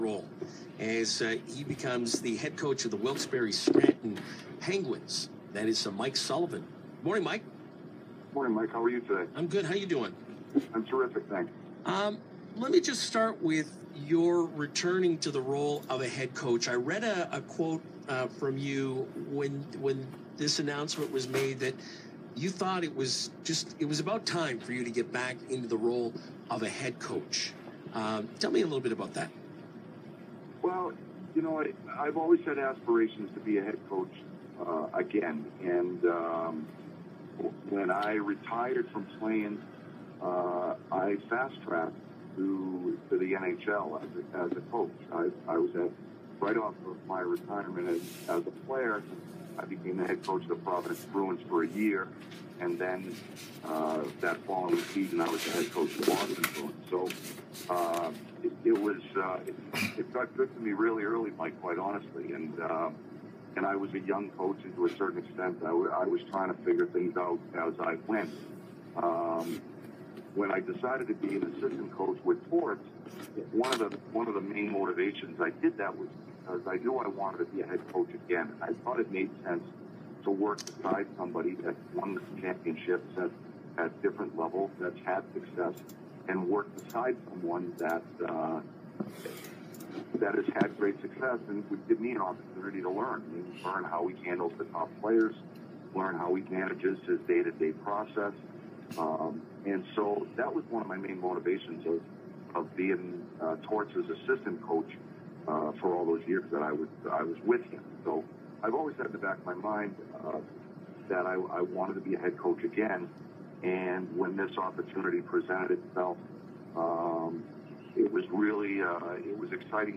I didn’t see it anywhere else on the internet, and I don’t even know if I am could post the audio, but it’s my audio I recorded on my iPod that I used to take off of Sirius XM which I am a subscriber to, but here is the Mike Sullivan interview from Thursday.
He sounded confident.